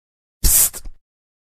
Psst Doors Sound Effect Free Download